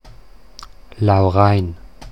Standarddeutsche Form
[lauˈrain]